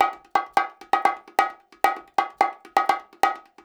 130BONGO 09.wav